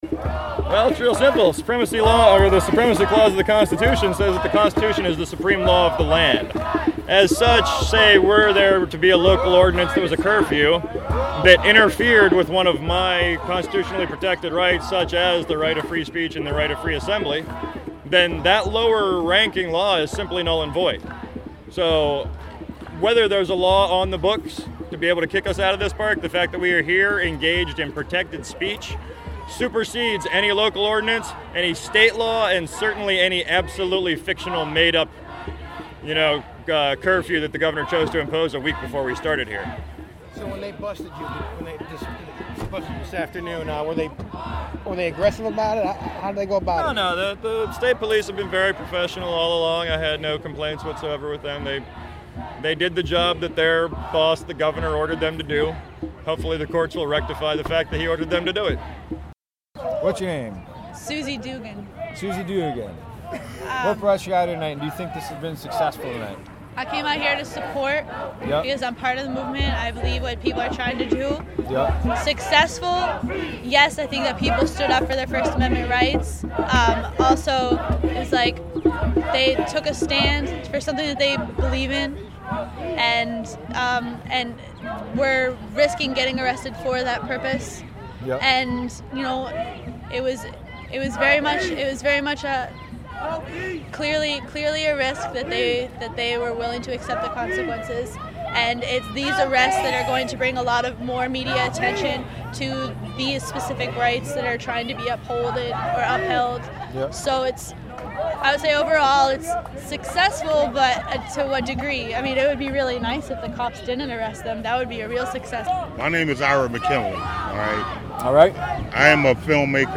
Occupy Albany field recordings, Nov. 12 Part 2 (Audio)